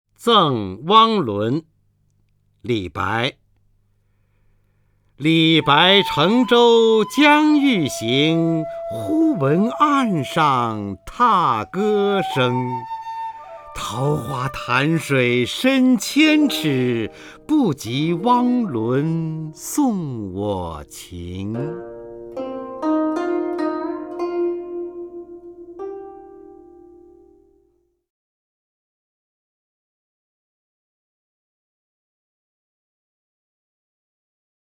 方明朗诵：《赠汪伦》(（唐）李白) （唐）李白 名家朗诵欣赏方明 语文PLUS